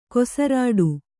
♪ kosarāḍu